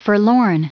Prononciation du mot forlorn en anglais (fichier audio)
Prononciation du mot : forlorn